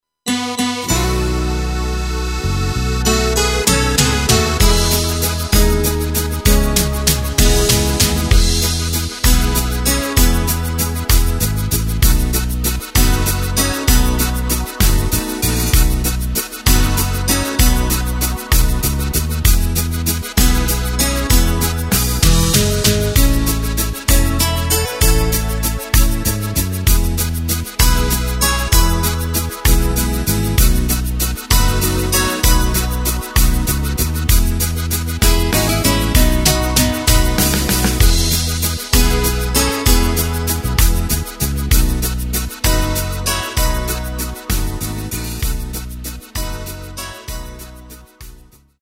Takt:          3/4
Tempo:         194.00
Tonart:            E